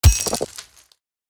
axe-mining-ore-10.ogg